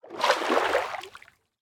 snapshot / assets / minecraft / sounds / liquid / swim17.ogg
swim17.ogg